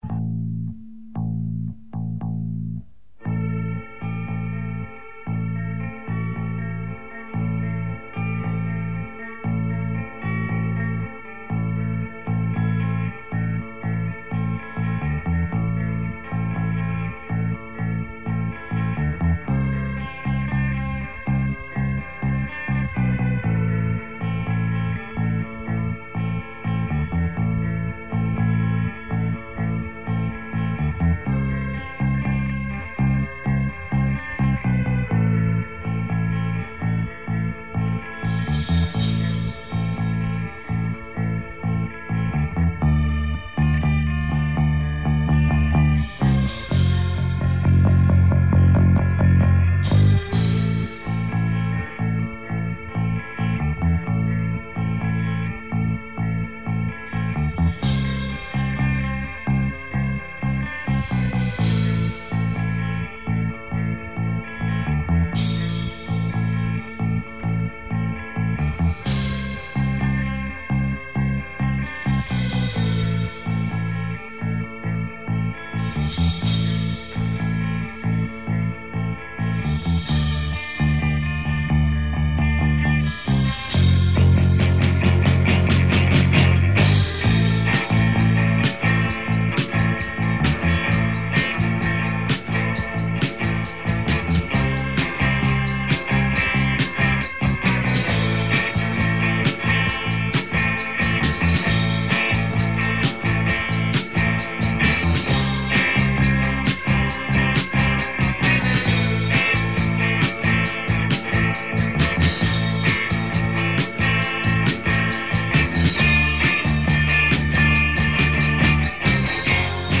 Bass
Drums, Keyboards
Guitar